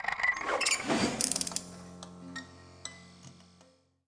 Menu Famous Sign Sound Effect
menu-famous-sign.mp3